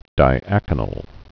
(dī-ăkə-nəl)